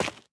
Minecraft Version Minecraft Version 1.21.5 Latest Release | Latest Snapshot 1.21.5 / assets / minecraft / sounds / block / dripstone / step3.ogg Compare With Compare With Latest Release | Latest Snapshot
step3.ogg